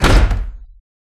Door6.ogg